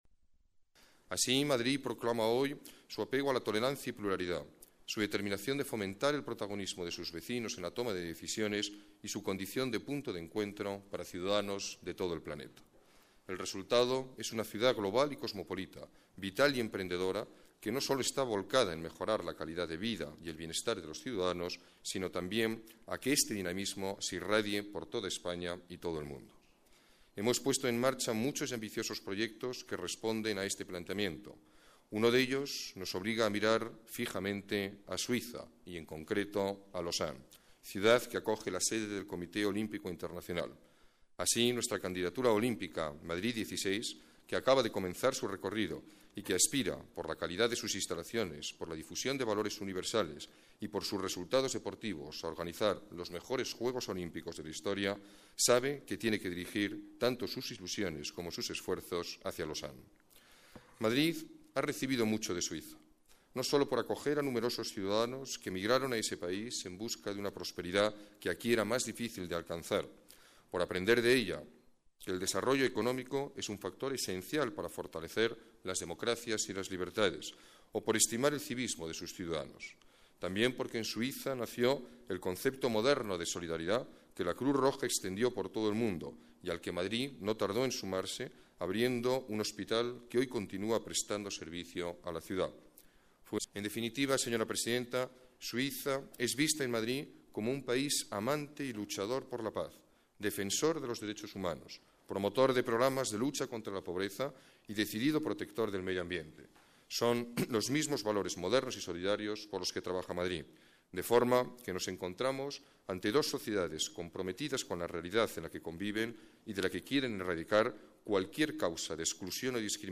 Nueva ventana:Intervención del alcalde de Madrid durante el acto de entrega del Título de Visitante Ilustre a la presidenta de la Confederación Helvética